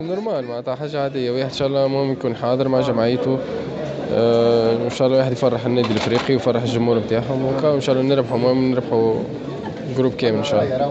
عقدت هيئة النادي الإفريقي اليوم ندوة صحفية بحديقة الرياضة "أ" لتقديم المنتدبين الجدد في المركاتو الشتوي الحالي و الحديث عن مقابلة الدربي أمام الترجي يوم الإربعاء القادم .